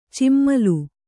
♪ cimmalu